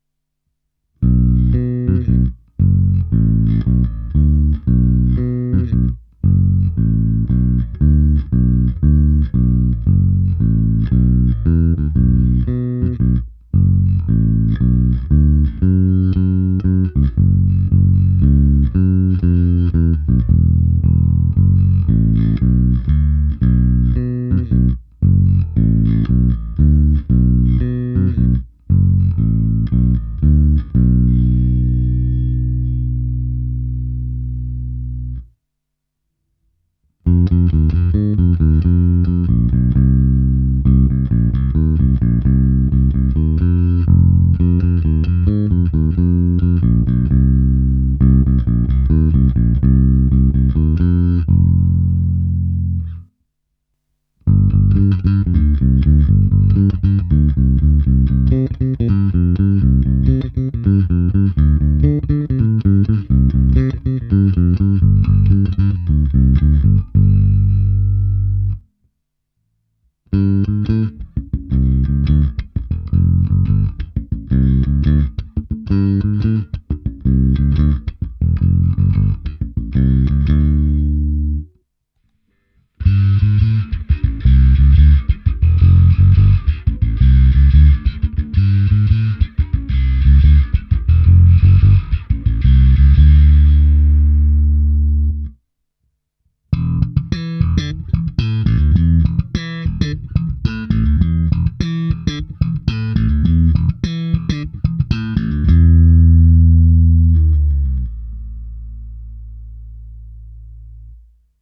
Nahrávka přes Darkglass Microtubes X Ultra se zapnutou simulací reproboxu, kompresorem, v jedné části je použito i zkreslení a slap. Hráno na oba snímače se skoro naplno přidanou aktivní elektronikou, ale trochu staženou tónovou clonou.